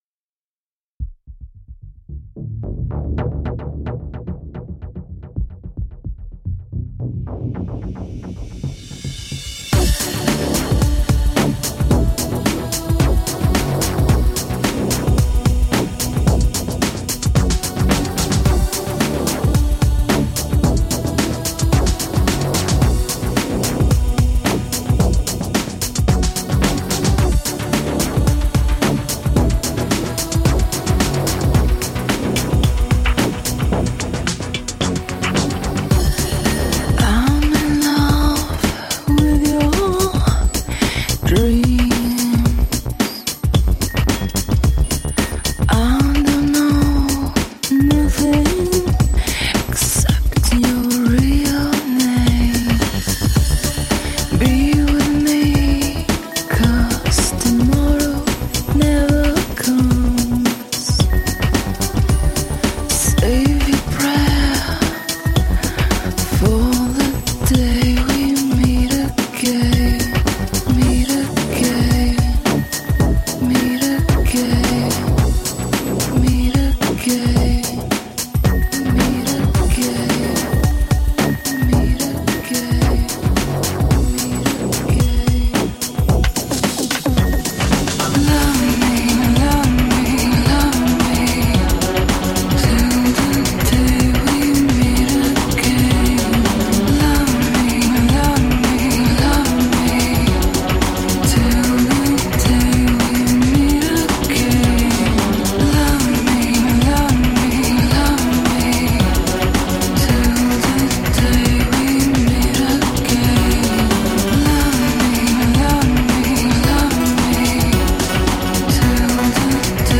Urban pop music for those about to dance.